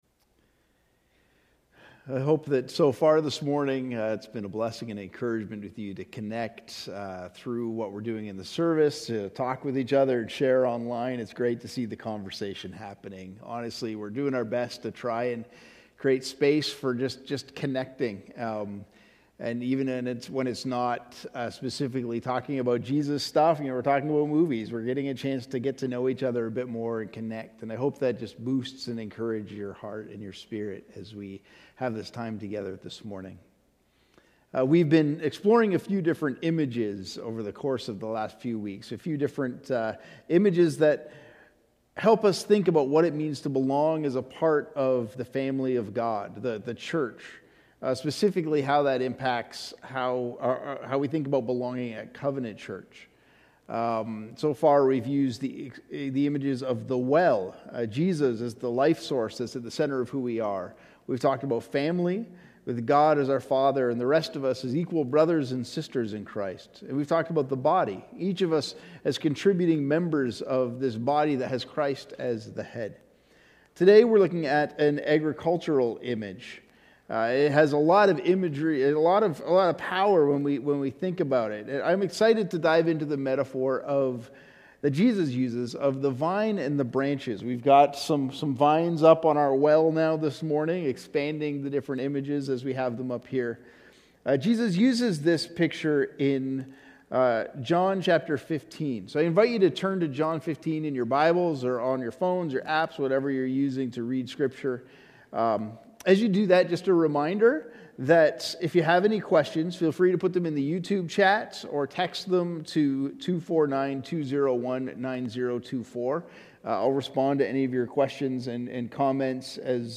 Sermons | Covenant Christian Community Church
We are so glad to have you with us for our Sunday Service.